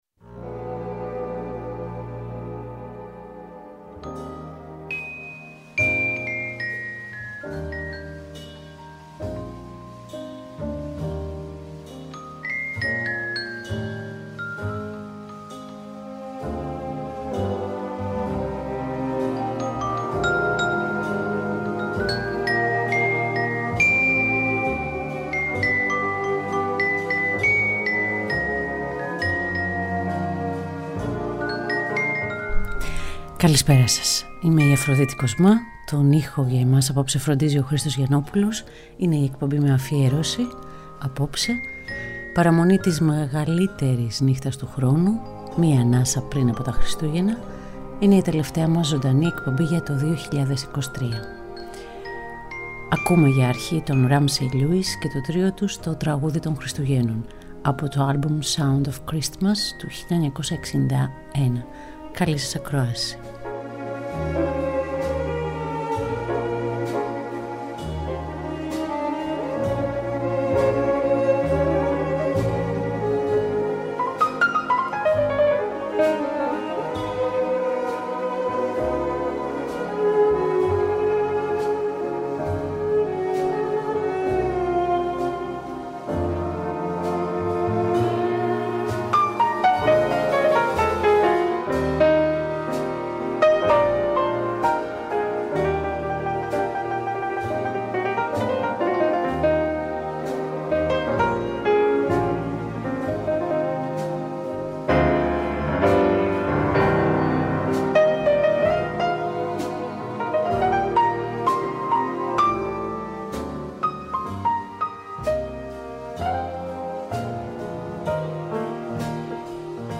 Ζωντανά από το στούντιο του Τρίτου Προγράμματος 90,9 & 95,6